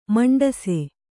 ♪ maṇḍase